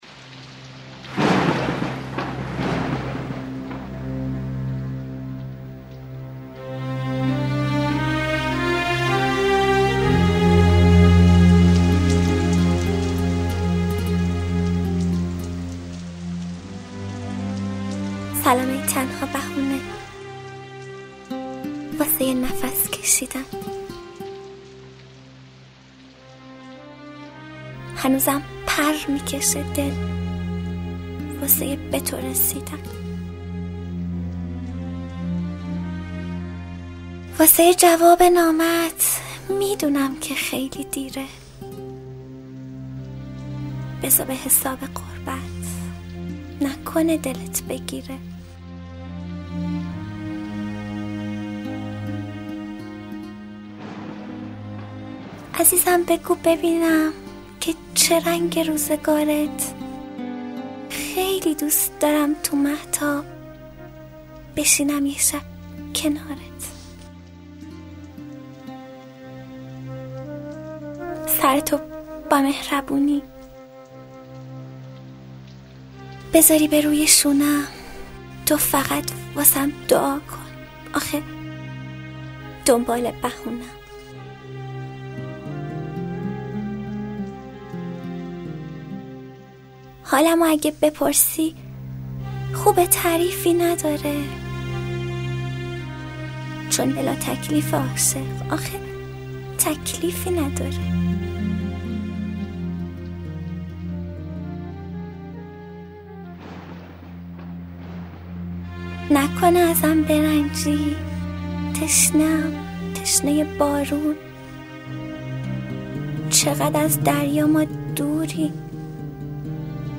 دکلمه شعر سلام ای تنها بهونه
توضیحات .: شاعر و گوینده: مریم حیدرزاده :.